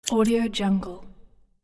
صدای برش قیچی، برای پروژه‌های خلاقانه و پرانرژی شما
• تنوع در جنس و اندازه قیچی: در این فایل، انواع مختلفی از صدای برش قیچی با جنس‌ها و اندازه‌های مختلف وجود دارد تا بتوانید بهترین گزینه را برای پروژه خود انتخاب کنید.
16-Bit Stereo, 44.1 kHz